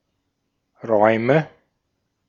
Ääntäminen
Ääntäminen Tuntematon aksentti: IPA: /ˈʀɔɪ̯mə/ Haettu sana löytyi näillä lähdekielillä: saksa Käännöksiä ei löytynyt valitulle kohdekielelle. Räume on sanan Raum monikko.